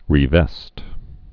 (rē-vĕst)